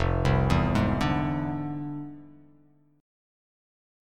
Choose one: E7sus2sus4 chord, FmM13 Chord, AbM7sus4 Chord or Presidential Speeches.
FmM13 Chord